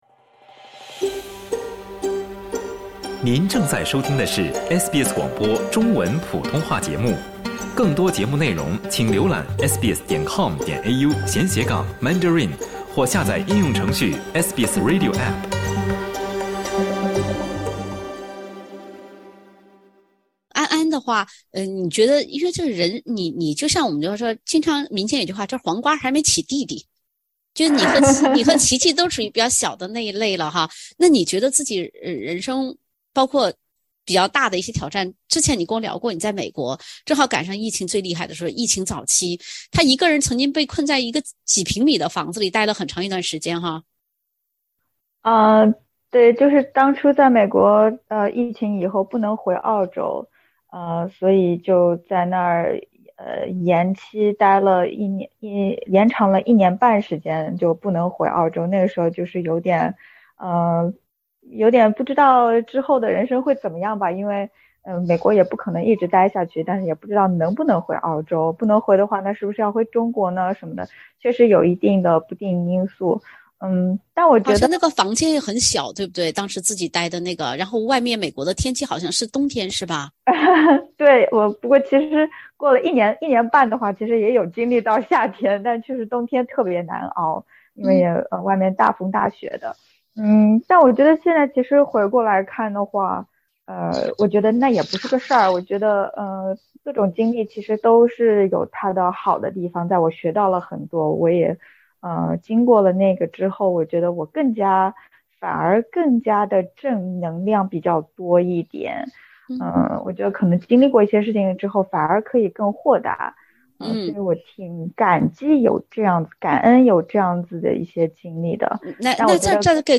SBS全新谈话类节目《对话后浪》，倾听普通人的烦恼，了解普通人的欢乐，走进普通人的生活。